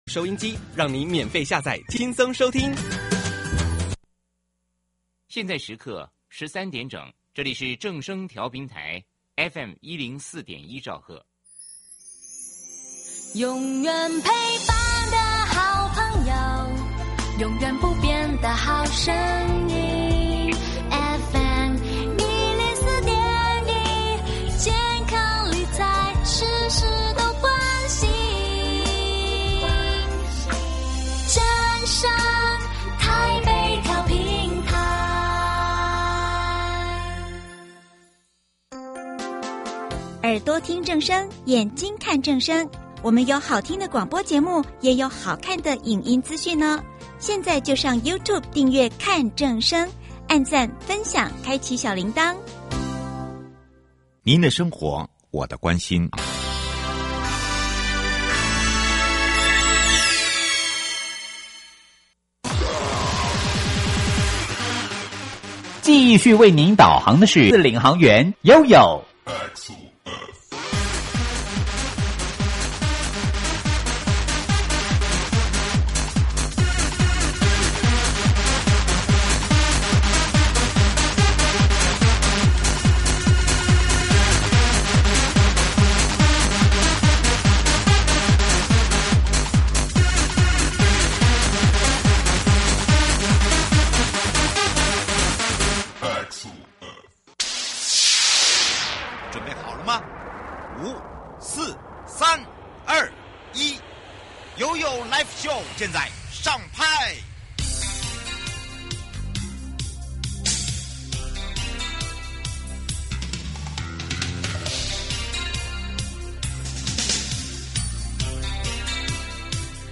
受訪者： 營建你我他 快樂平安行~七嘴八舌講清楚~樂活街道自在同行! 主題：人本交通及校園教育宣導(一) 人本